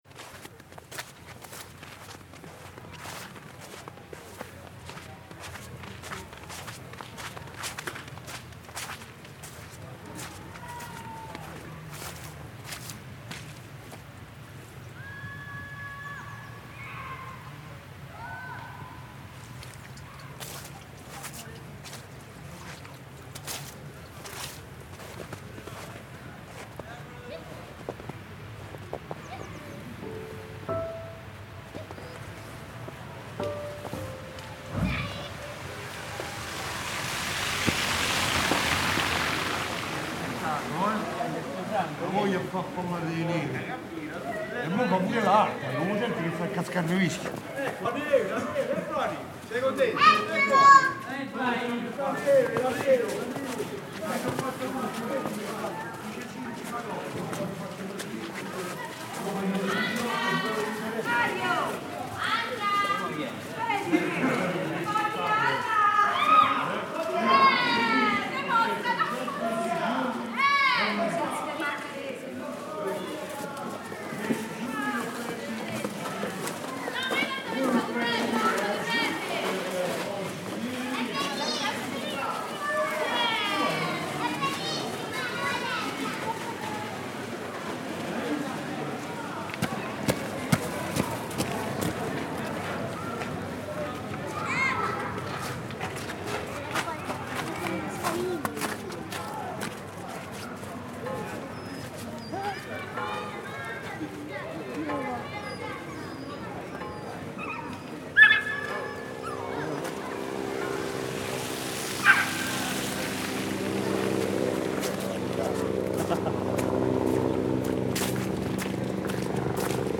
Oggi il mio paese suona diversamente.
é cordiale e gioioso nel vedersi bianco; tutti parlano della neve.
Con passi morbidi attraverso le vie di Tivoli; entusiasmo e disagio.
Mi incammino lungo il fiume e mi fermo ad ascoltare il disgelo.
Registrazioni effettuate il 4 e 5 febbraio 2012 presso:
lungo il fiume Aniene
tivoli-neve-2012.mp3